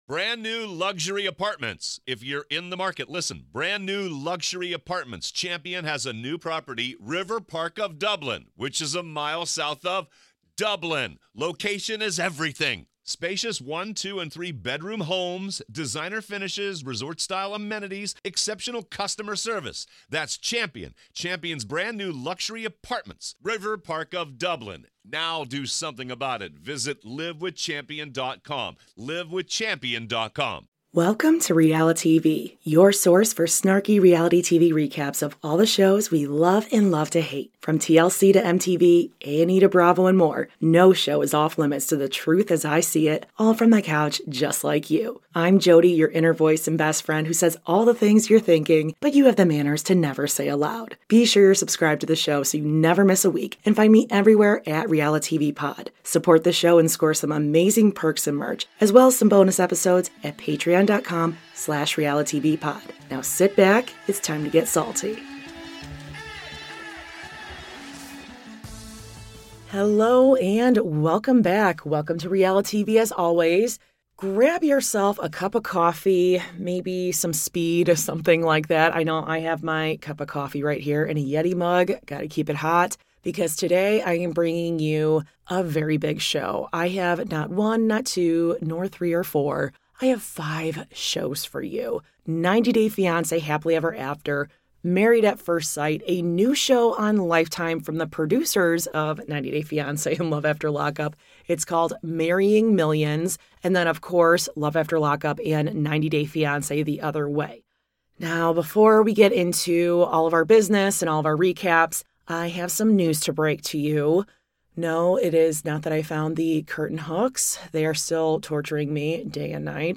Oh yes, I’m bringing you the best & worst moments from this week’s craziest shows. *Apologies for any sound issues.